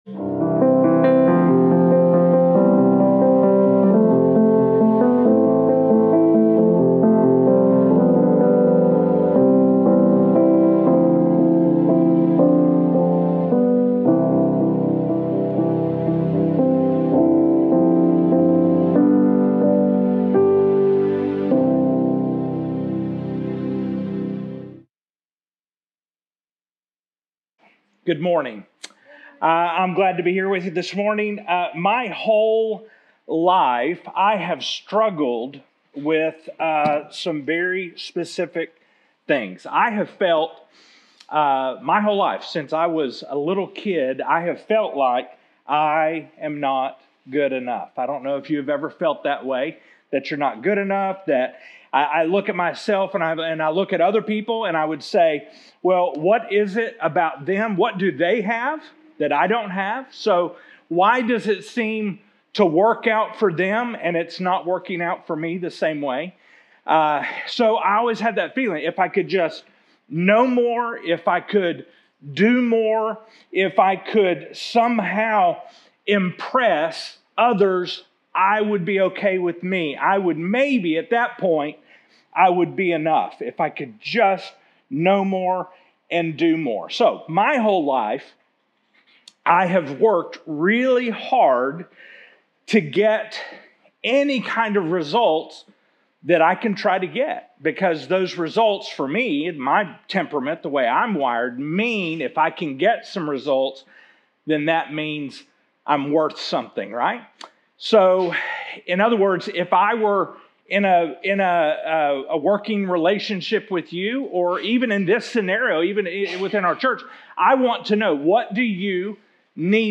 2024 Current Sermon 5 OF 9